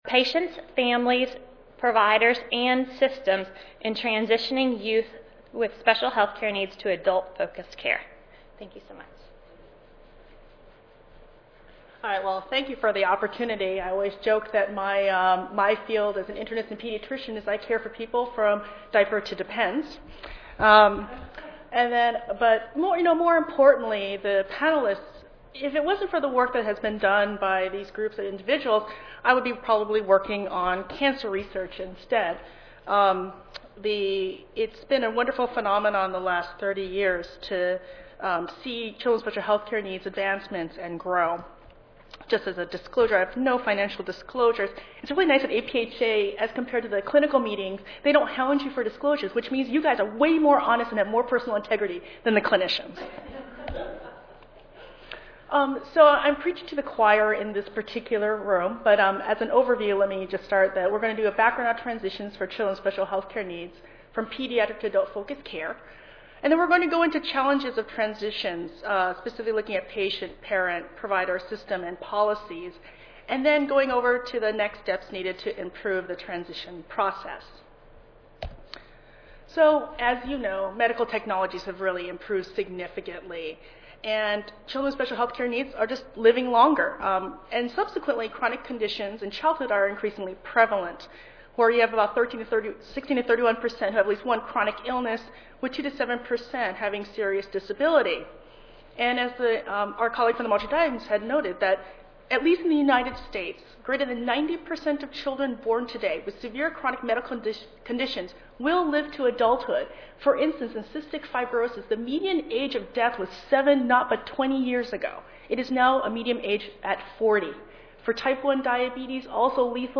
5058.0 Today's Challenges, Tomorrow's Promise: Children with Special Healthcare Needs Worldwide Wednesday, November 6, 2013: 8:30 a.m. - 10:00 a.m. Panel Discussion This session will highlight findings from national and international activities and studies on issues facing children with special health care needs (e.g., asthma, birth defects, developmental delays and disabilities), their families, public health practitioners and researchers, and health care providers.
Panelists will include members from academia, federal and state public health agencies, non-profit organizations, and advocacy groups.